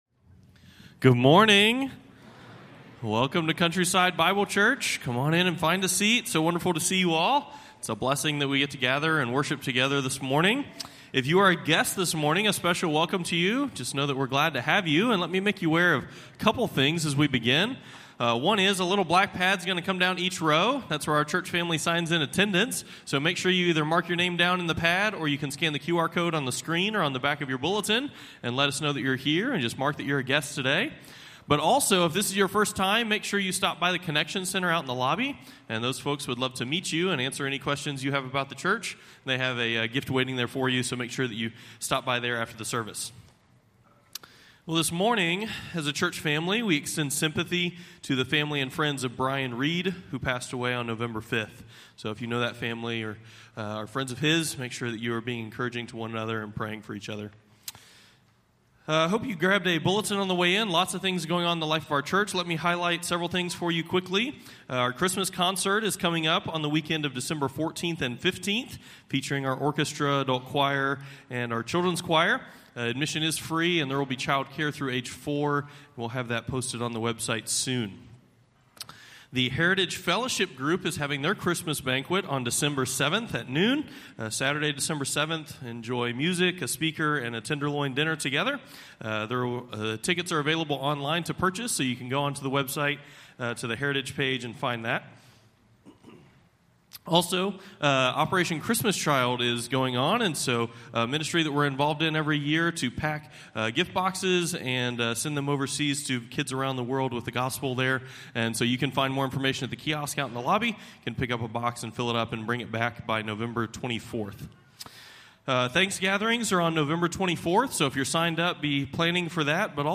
Morning Worship Service